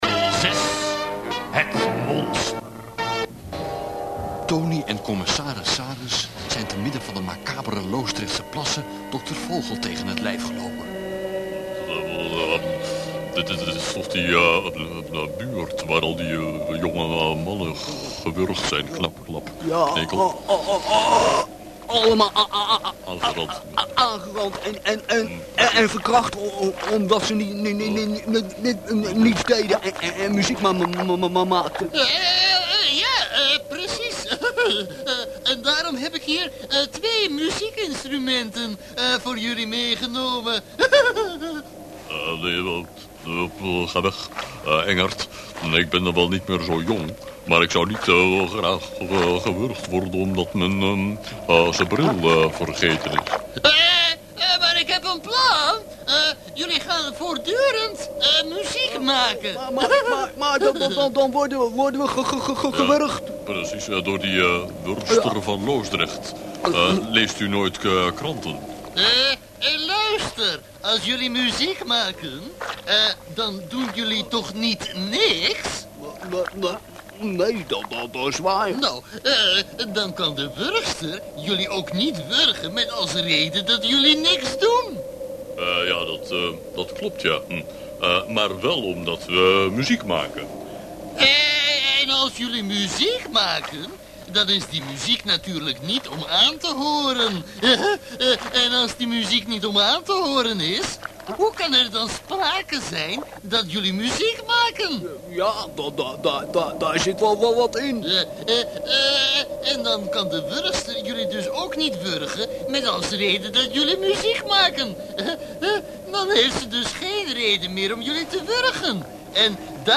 Ik vond in een oude doos een paar tapes met mono opnames van crappy kwaliteit.